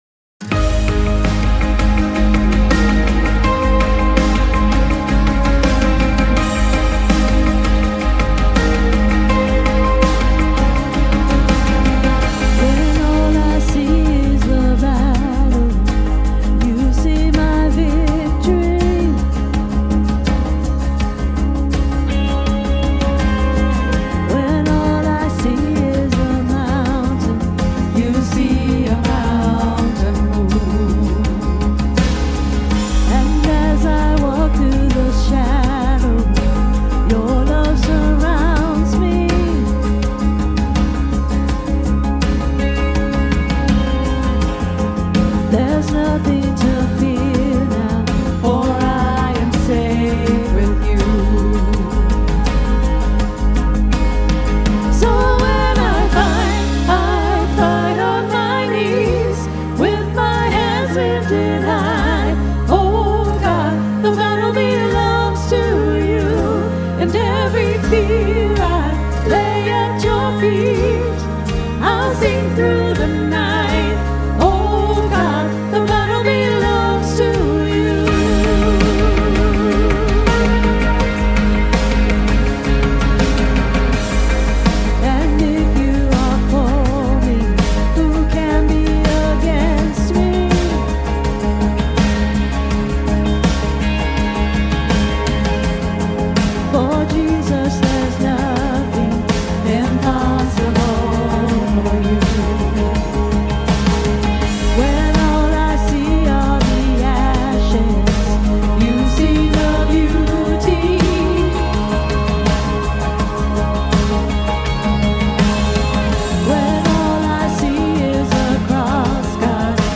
Praise Team